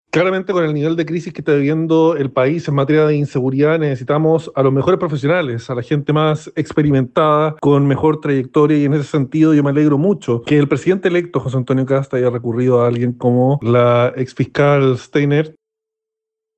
Por el contrario, el parlamentario aseguró que la incorporación de Steinert al próximo gabinete es una buena señal para contribuir en materia de seguridad.